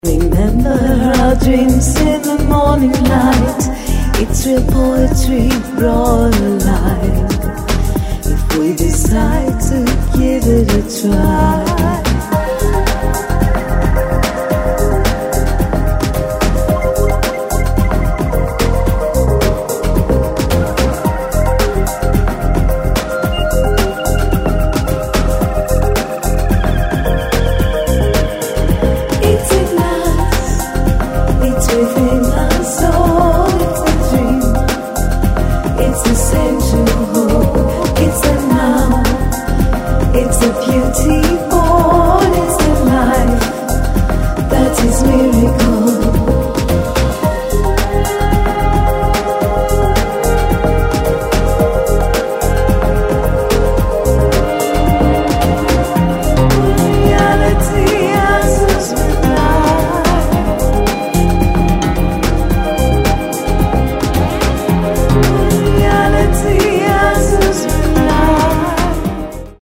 IDM/Electronica, Trance